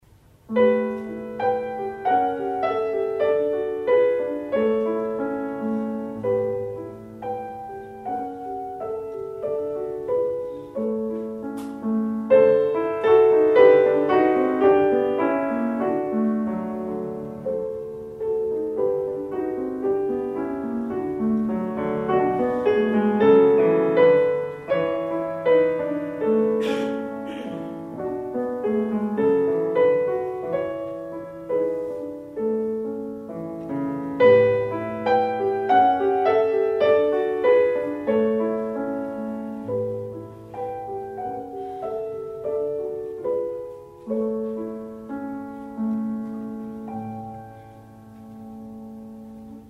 Live recordings